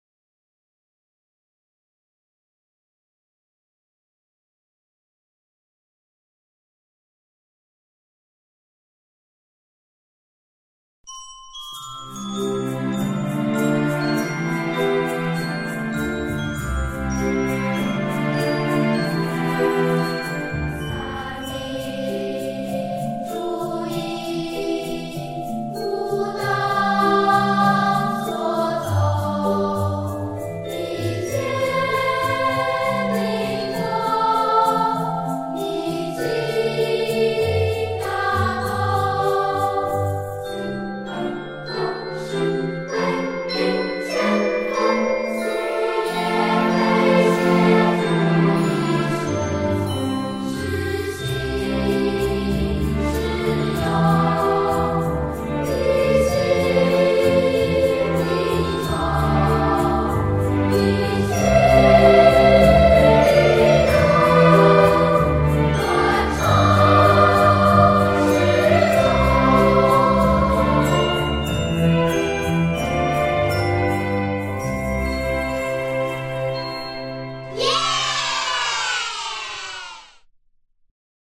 相關檔案（本單元樂曲係由著作權人國家表演藝術中心國家交響樂團及國立實驗合唱團所演奏及演唱，經同意授權僅供非營利下載使用。）